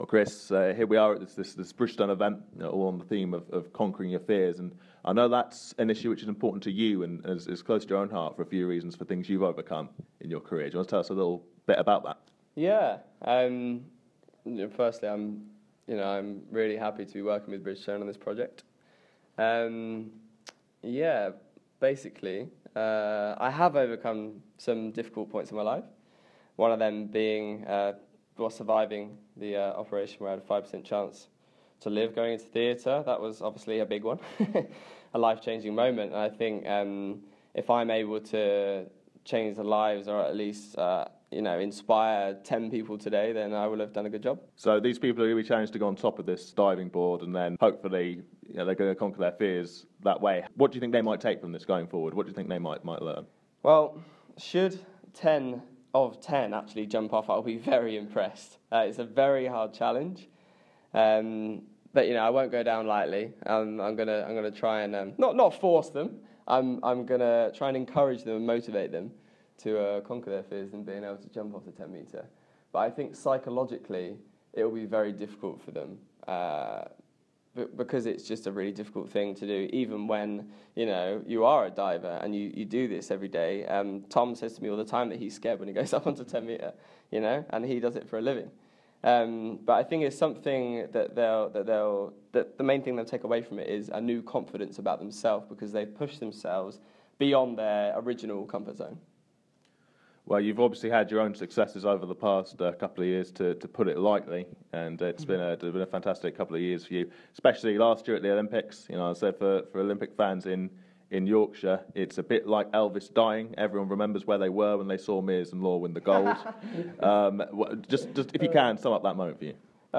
Speaking at an event as part of Bridgestone's 'Chase Your Dreams, No Matter What' campaign